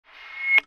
Звуки рупора
Короткий альтернативный вариант